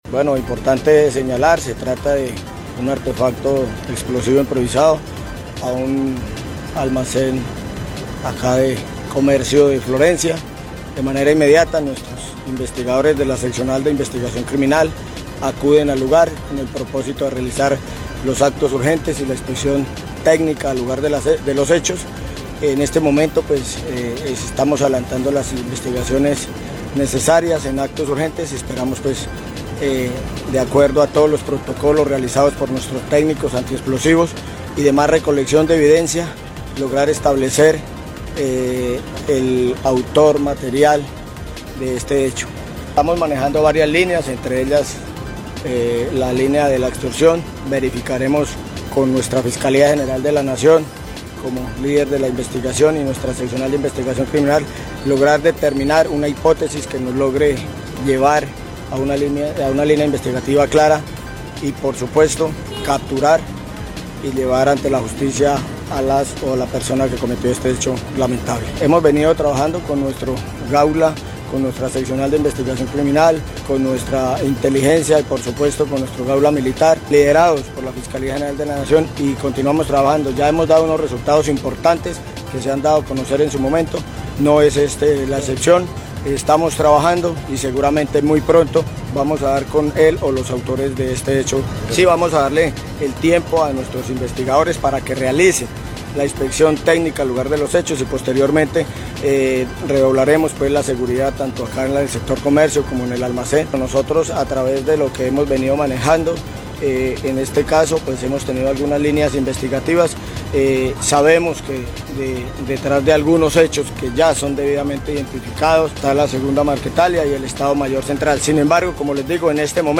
Sobre el caso, el coronel Julio Guerrero, comandante encargado de la Policía Caquetá, dijo que, esta sería una de las líneas investigativas donde hechos similares han sido ejecutados por las disidencias de las denominadas Segunda Marquetalia.